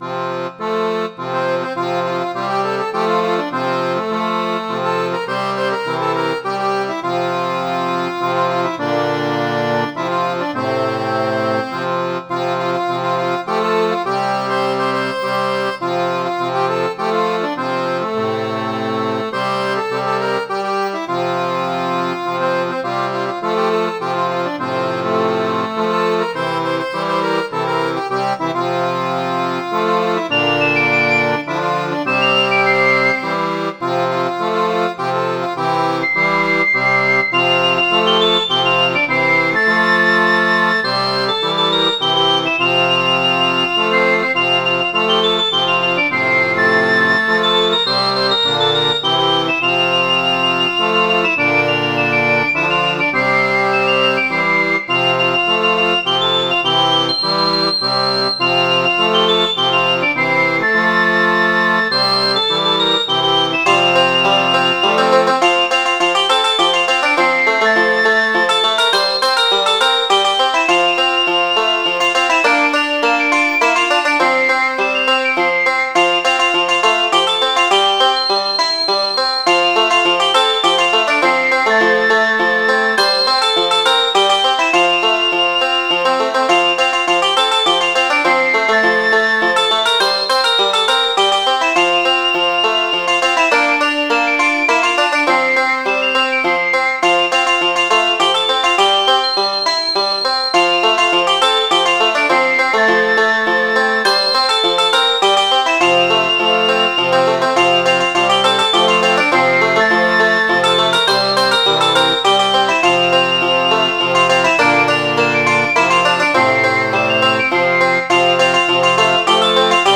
Midi File, Lyrics and Information to Paddy, Get Back